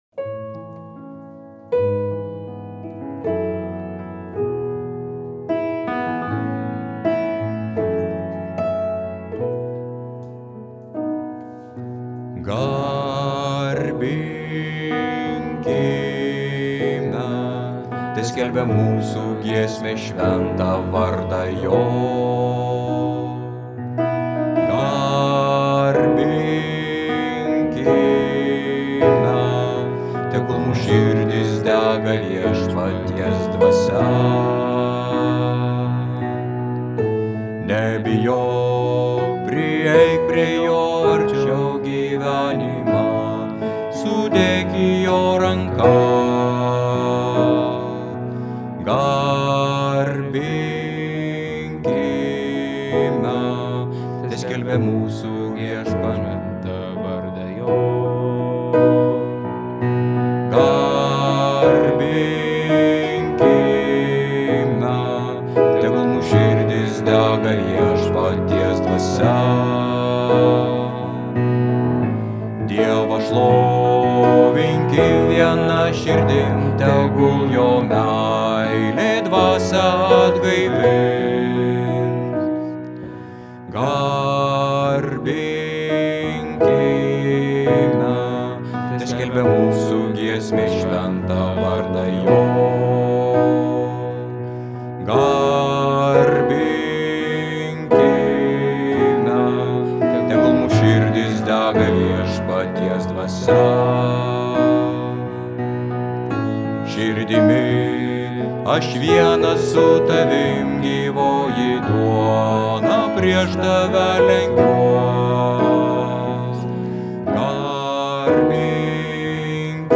Bosas: